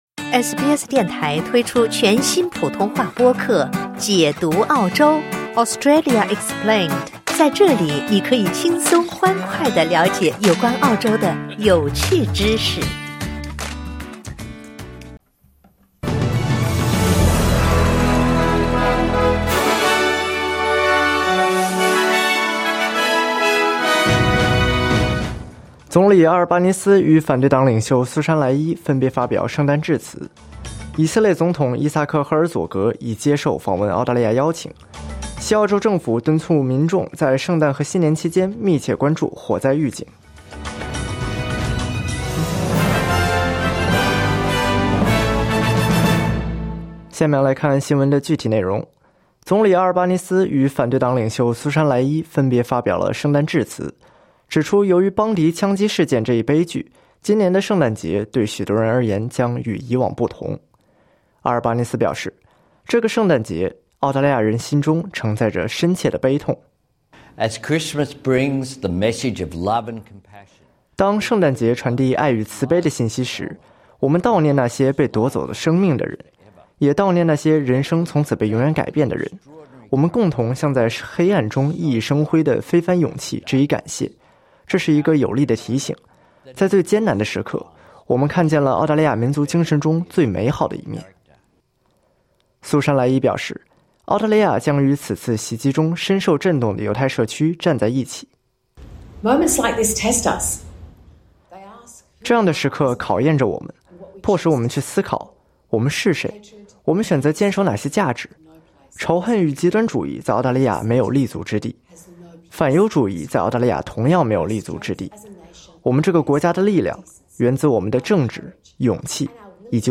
SBS早新闻（2025年12月24日）